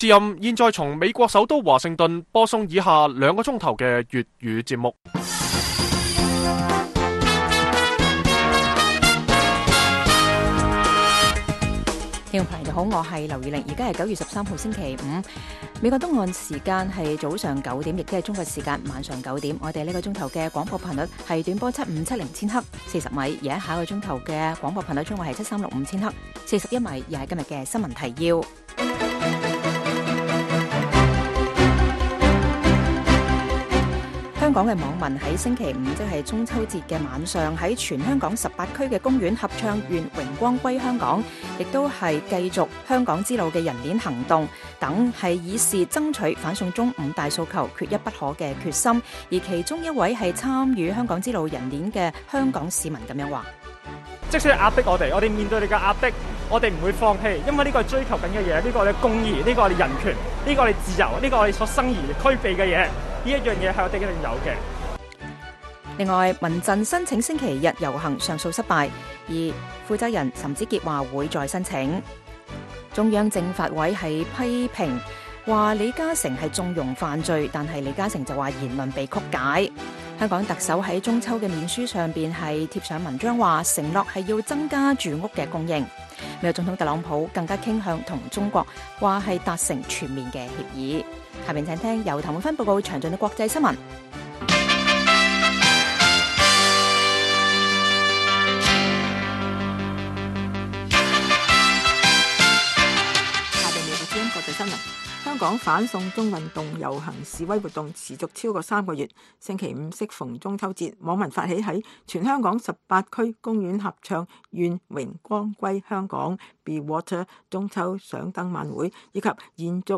北京時間每晚9－10點 (1300-1400 UTC)粵語廣播節目。